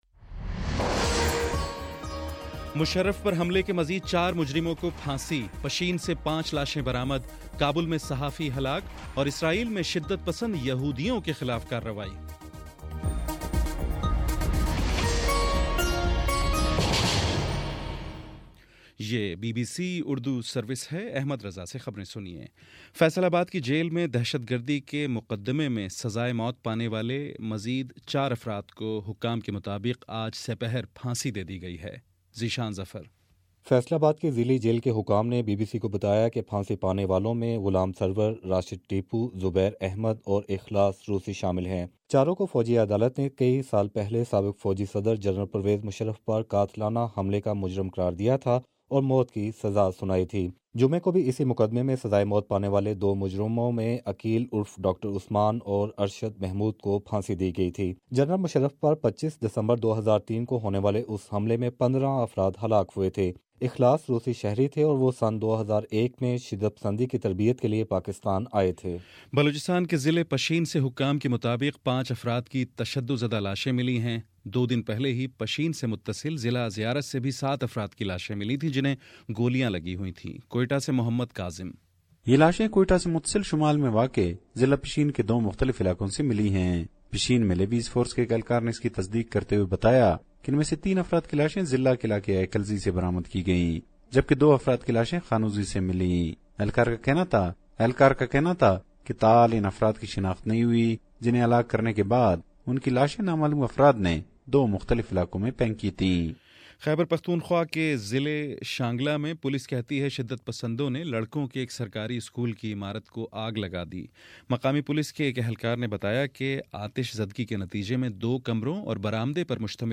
دسمبر21: شام چھ بجے کا نیوز بُلیٹن